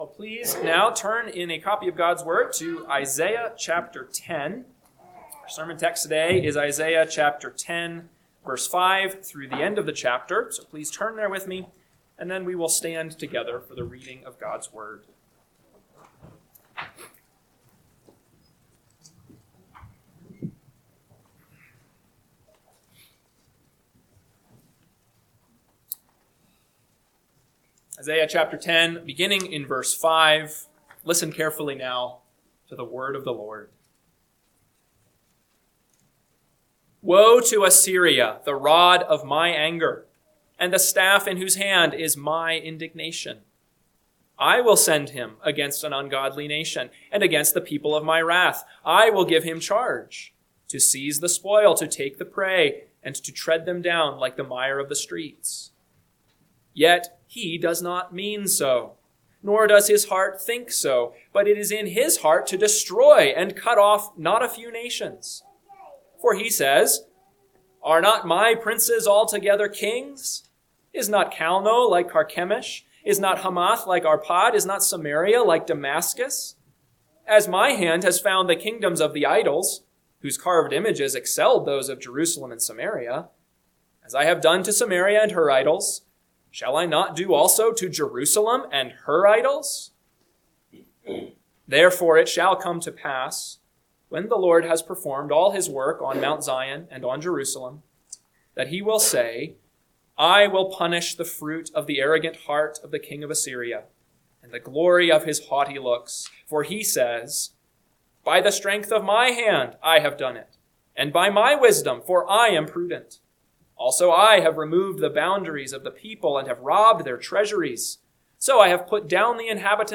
AM Sermon – 1/25/2026 – Isaiah 10:5-34 – Northwoods Sermons